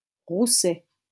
Roussay (French pronunciation: [ʁusɛ]